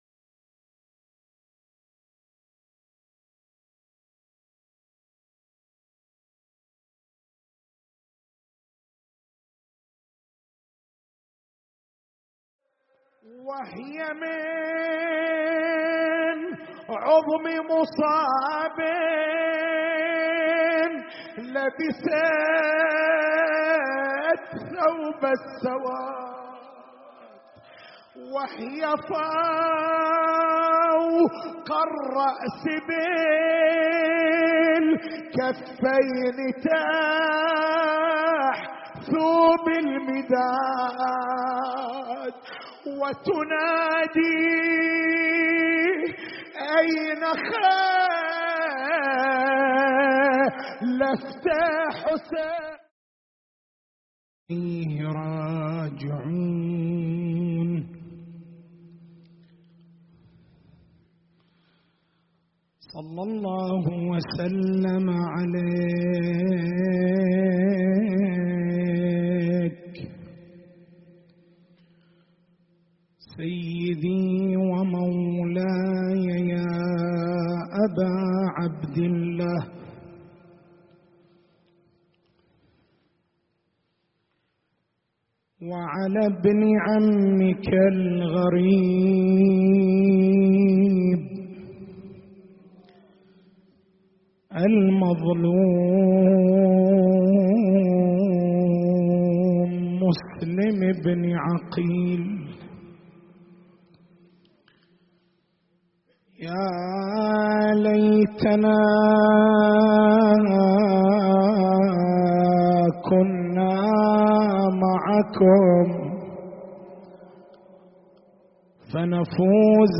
تاريخ المحاضرة: 04/01/1444 نقاط البحث: ما معنى الرقابة الاجتماعية؟ ما هو موقف الإسلام من الرقابة الاجتماعية؟
حسينية بن جمعة بالكويكب